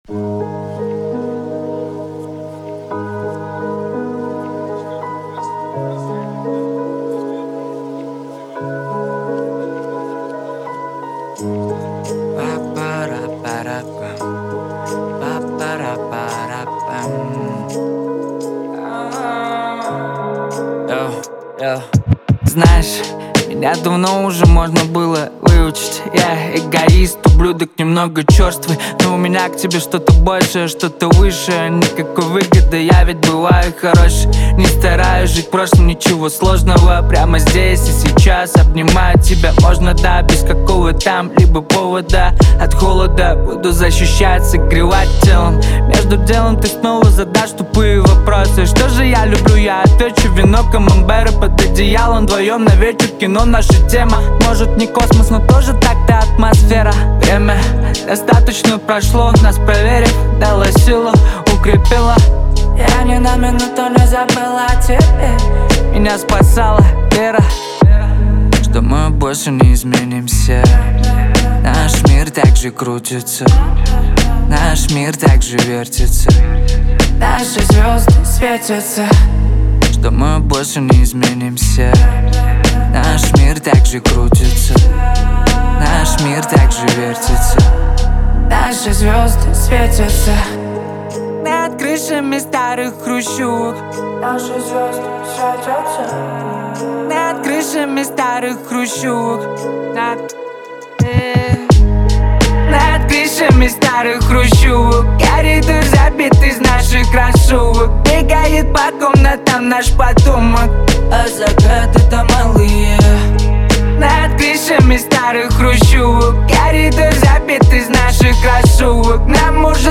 Качество: 320 kbps, stereo
Русский рэп 2025